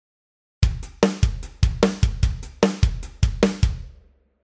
バスドラを裏で踏む
最初以外はバスドラを裏で踏む、ドッタド　ッドタドのパターンです。